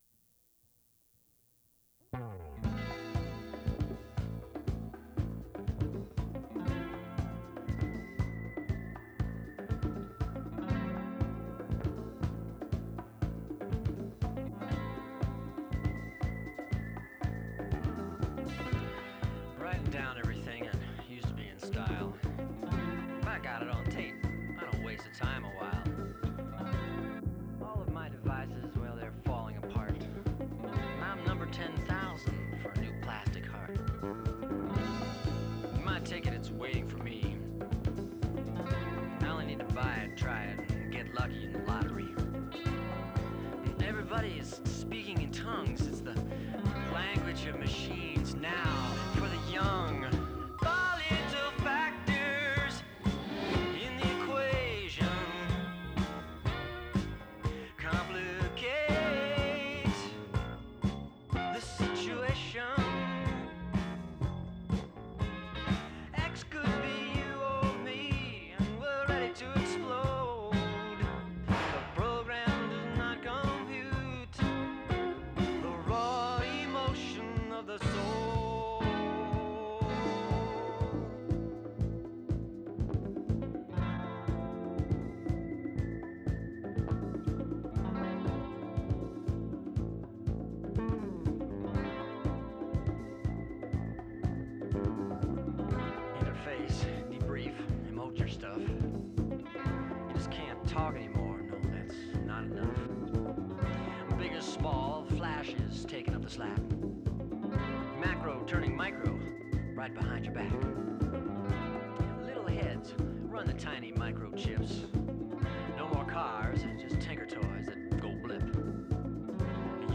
Guitars
Bass
Drums
Keyboards